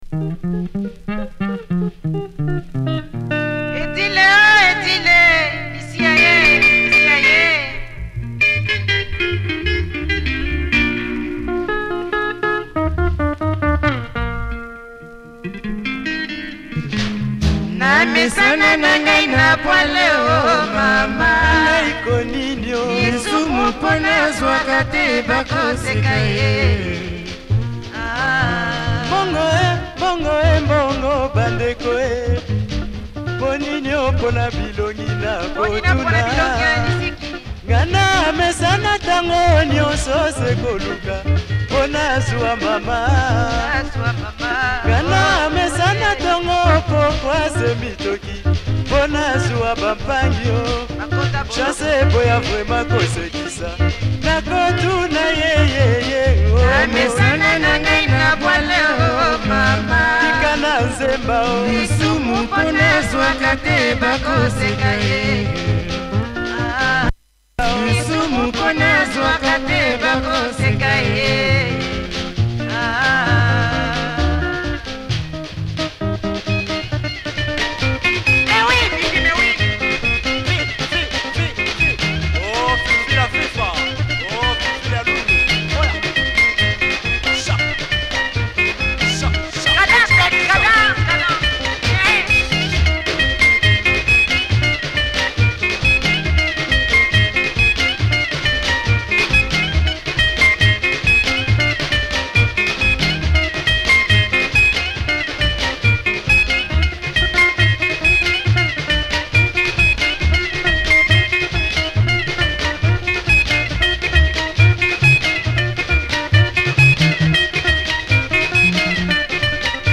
Proper Lingala